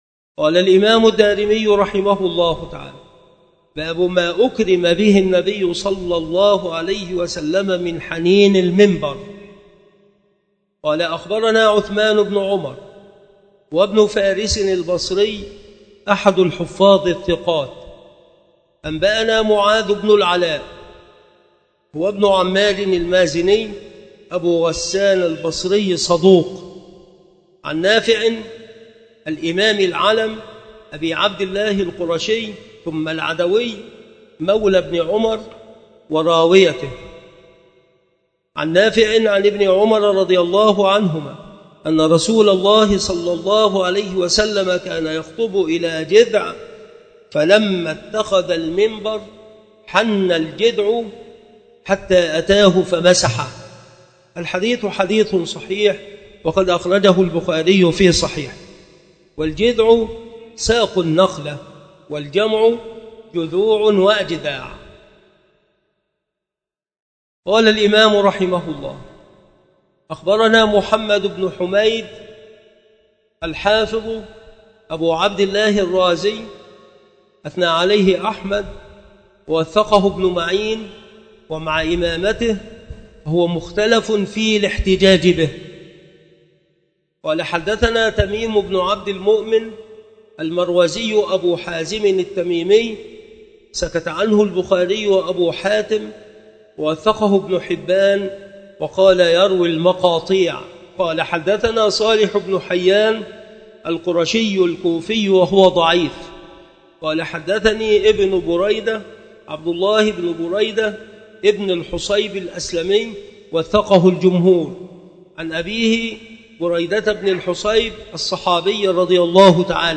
مكان إلقاء هذه المحاضرة بالمسجد الشرقي بسبك الأحد - أشمون - محافظة المنوفية - مصر
شروح الحديث